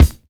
DJP_KICK_ (83).wav